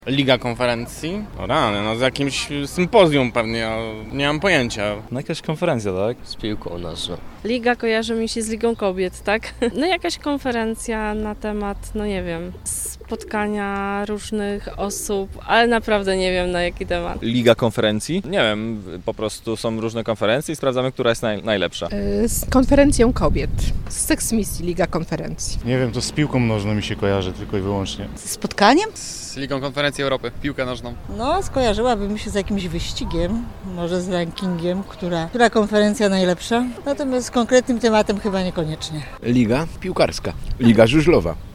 Zapytaliśmy Dolnoślązaków, czy słyszeli o tych rozgrywkach i z czym kojarzy im się ich nazwa.
liga-konferencji-sonda2.mp3